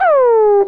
alarm_siren_loop_06.wav